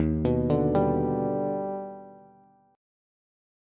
D#+M7 Chord
Listen to D#+M7 strummed